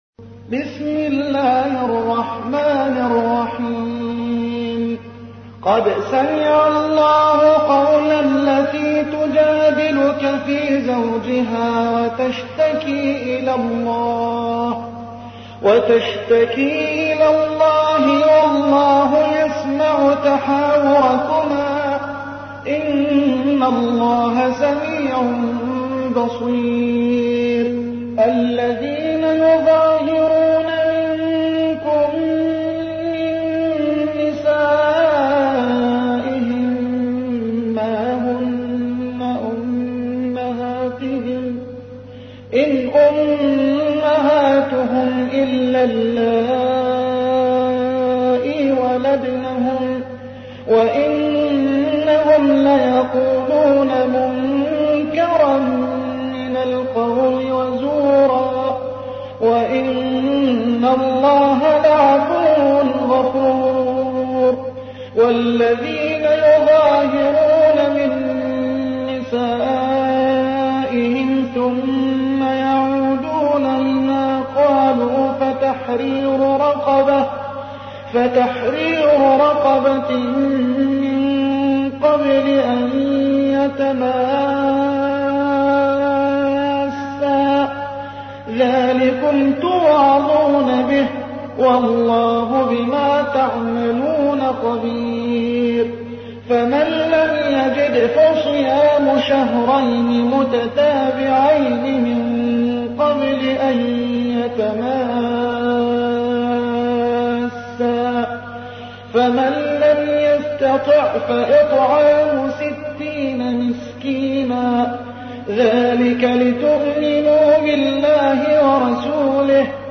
تحميل : 58. سورة المجادلة / القارئ محمد حسان / القرآن الكريم / موقع يا حسين